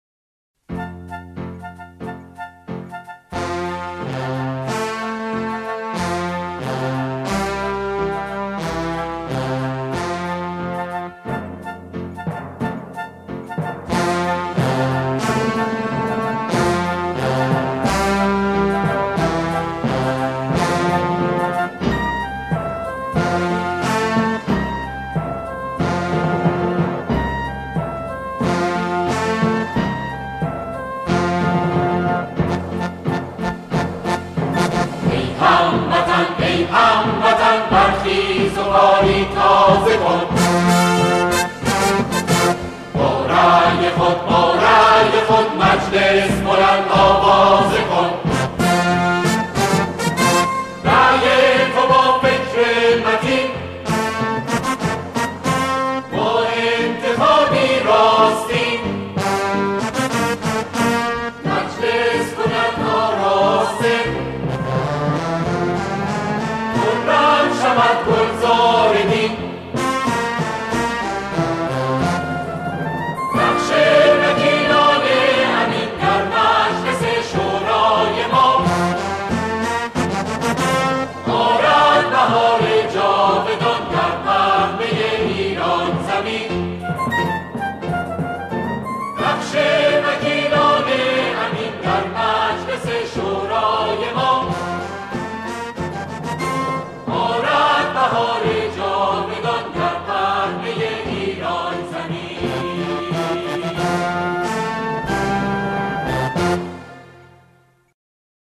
همخوانی کرده‌اند